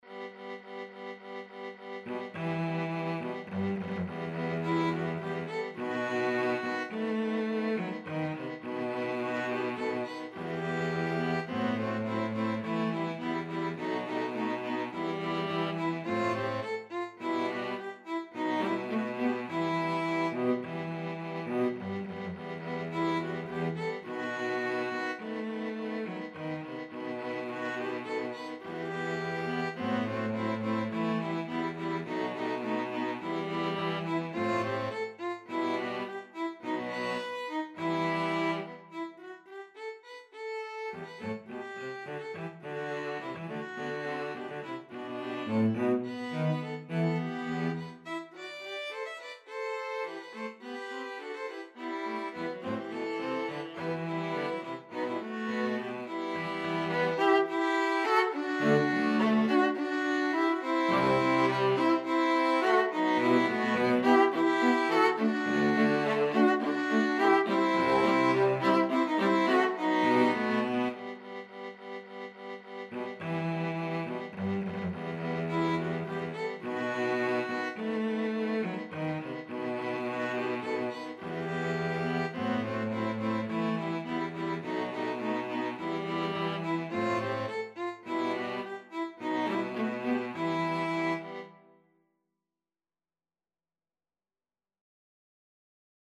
Persia II - for string quartet, a "sequel"